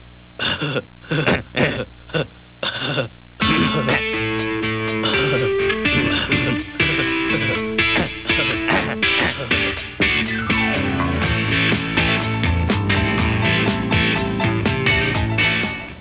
Guitar Intro Wanna play along?